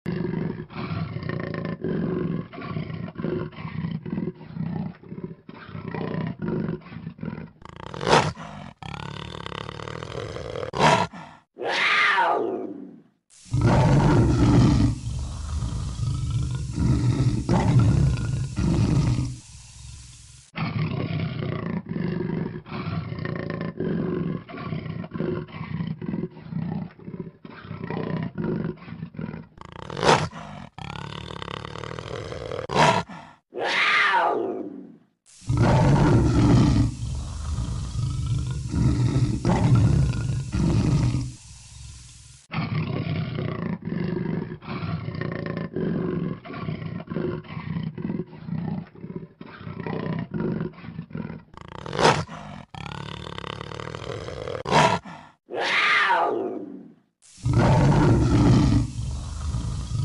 Tiếng Báo Gầm Gừ MP3